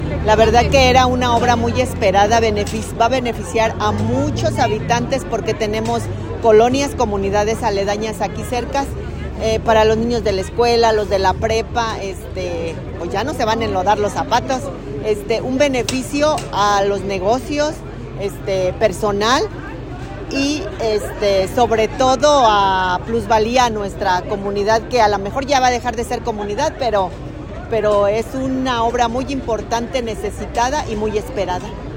AudioBoletines
habitante de la comunidad